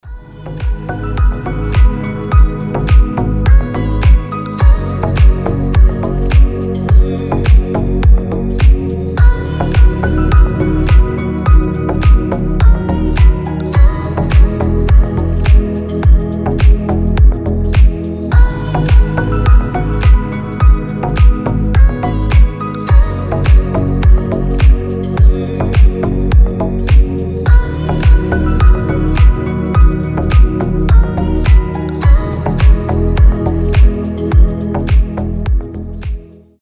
• Качество: 320, Stereo
красивые
deep house
милые
спокойные
chillout